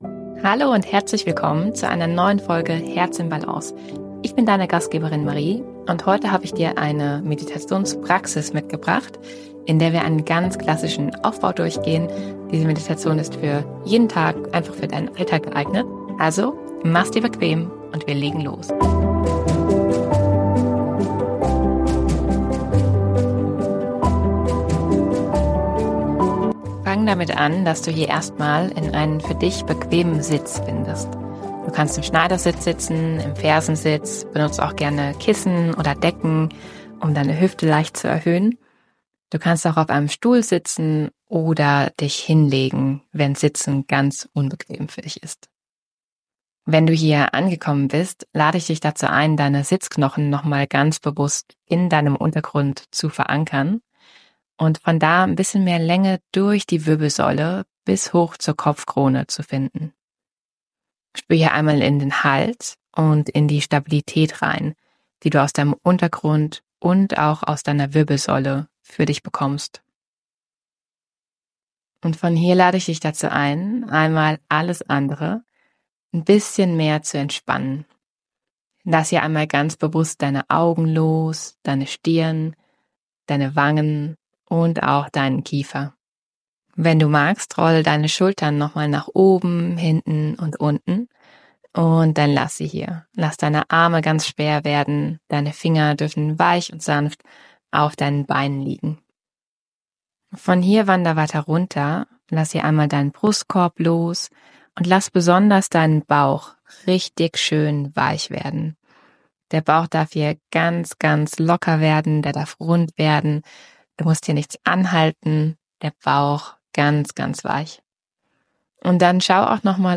Geleitete Meditation für jeden Tag - Dankbarkeit im Alltag ~ Herz in Balance - Ruhe & Klarheit im Alltag: Yoga | Meditation | Natur Podcast